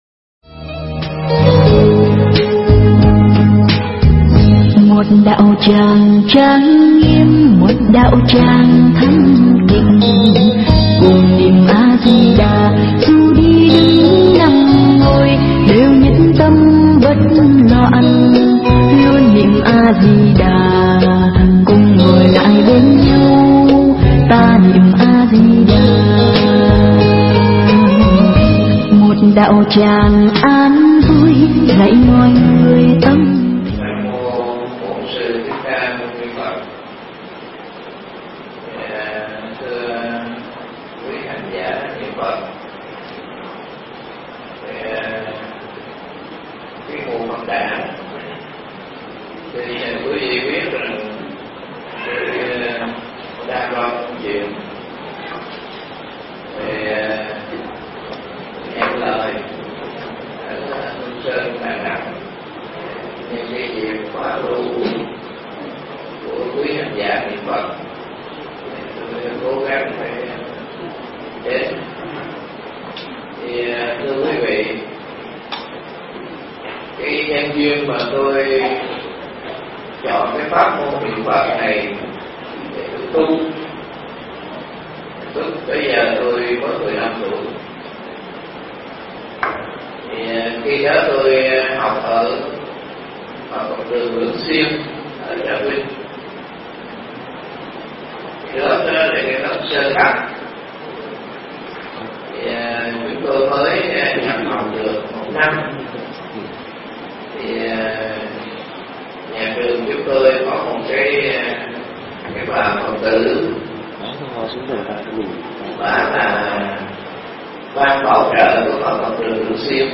Mp3 Thuyết pháp Hãy Chọn Pháp Tu